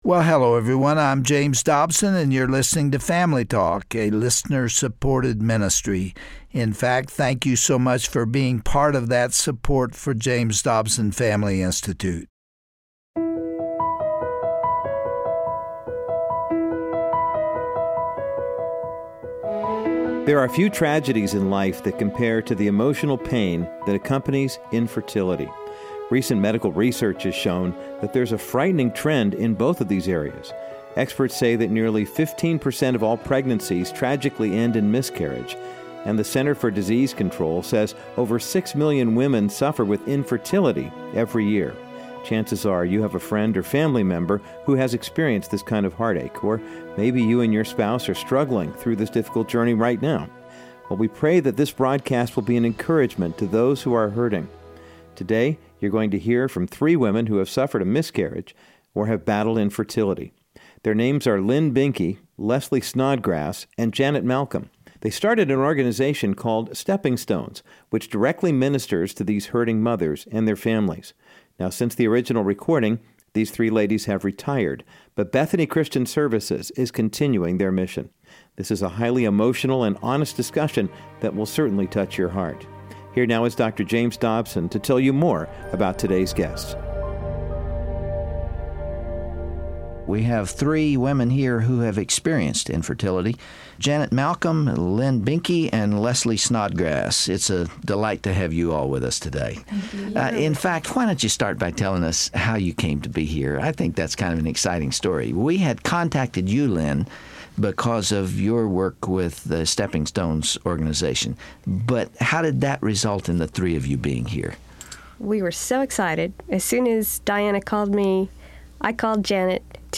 Today on¬†Family Talk, Dr. Dobson sits down with three women whose lives were forever changed by infertility or the death of an unborn child. They each share their difficult stories, and how they continued to trust God through their pain.